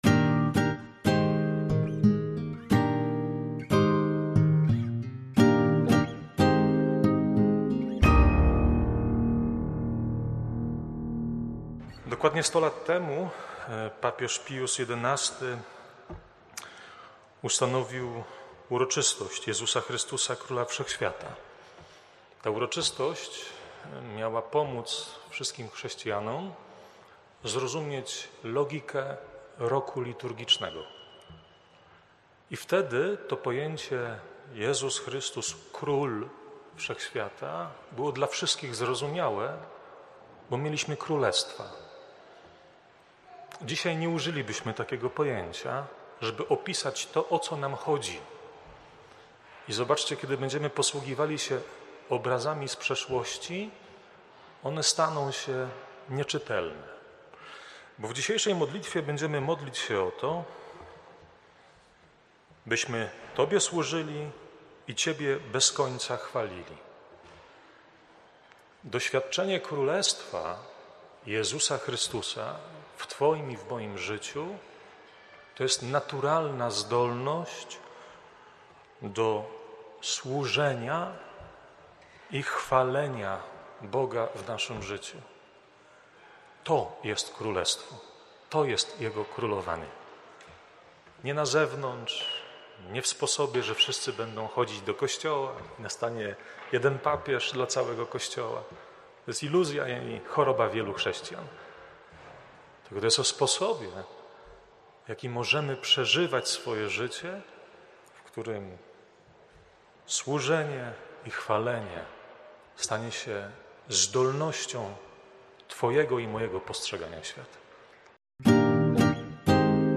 wprowadzenie do Liturgii, oraz kazanie: